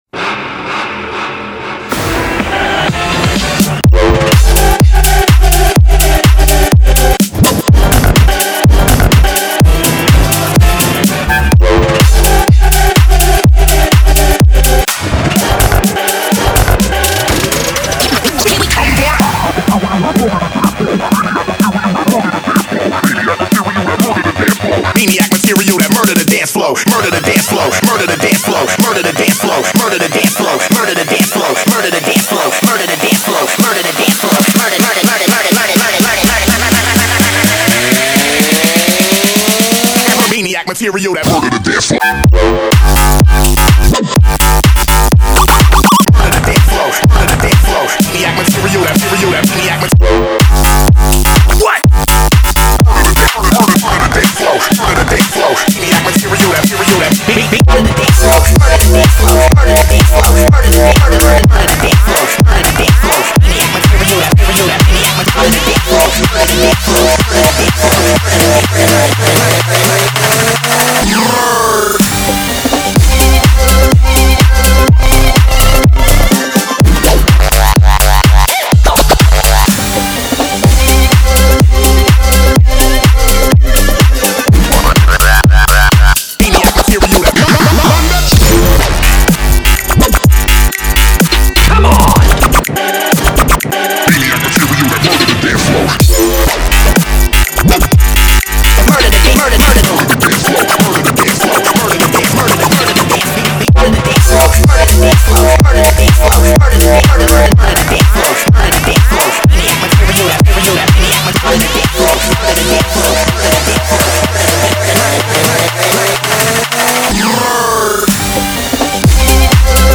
BPM63-125
Audio QualityPerfect (High Quality)